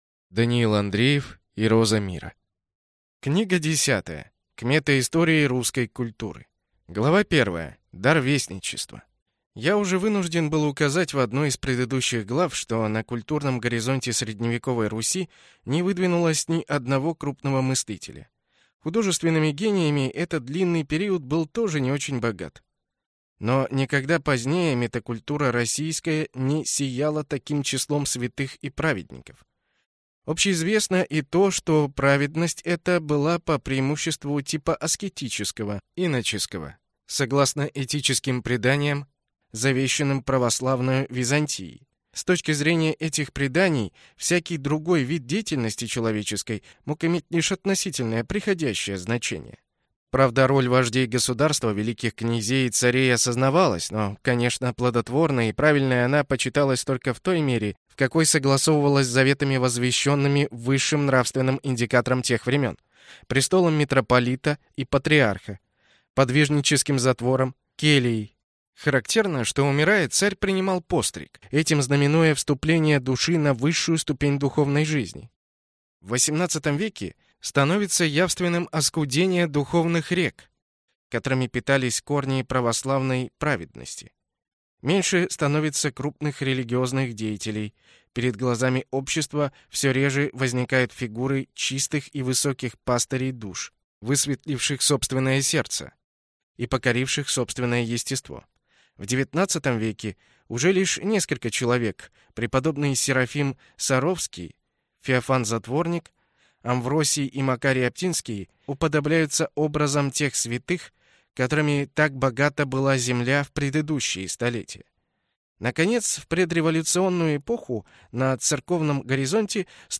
Аудиокнига Роза мира. 3-я часть | Библиотека аудиокниг